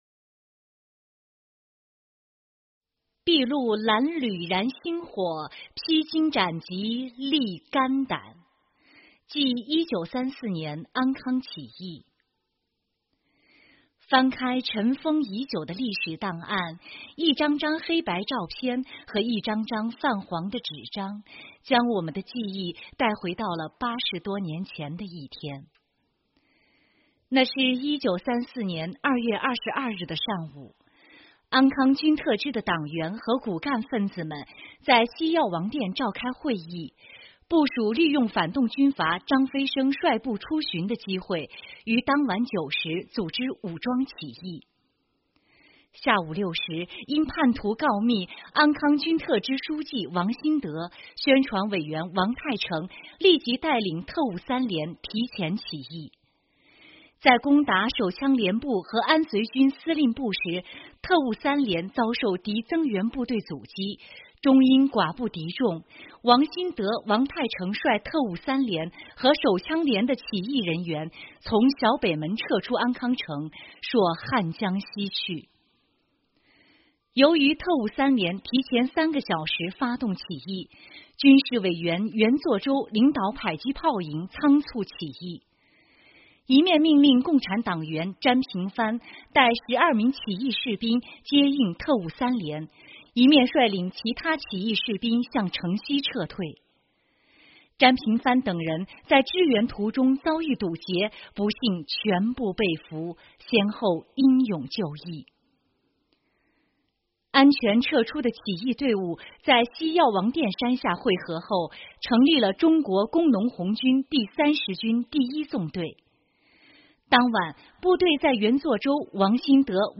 【红色档案诵读展播】筚路蓝缕燃星火，披荆斩棘沥肝胆——记1934年安康起义